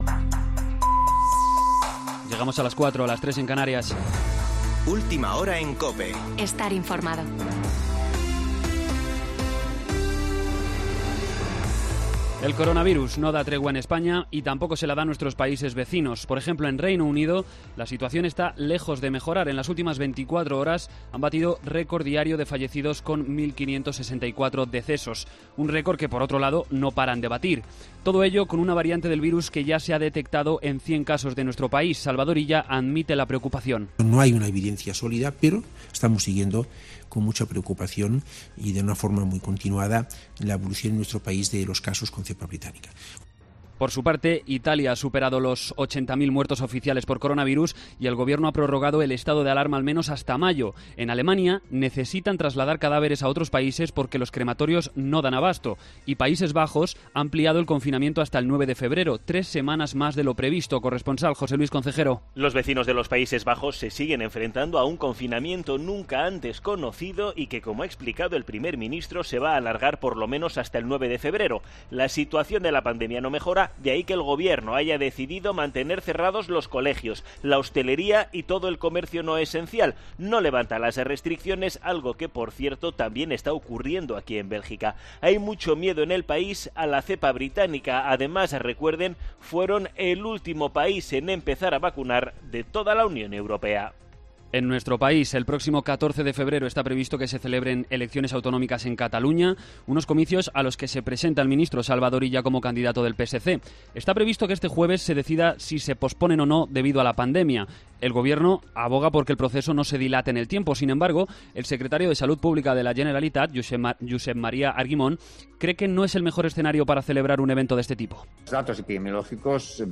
AUDIO: Boletín de noticias COPE del 14 de enero de 2020 a las 04.00 horas